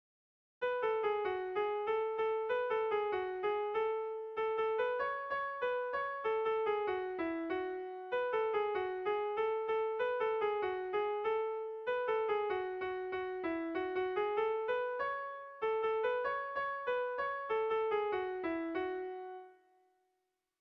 Bertso melodies - View details   To know more about this section
Kontakizunezkoa
Hamarreko txikia (hg) / Bost puntuko txikia (ip)
ABAA2B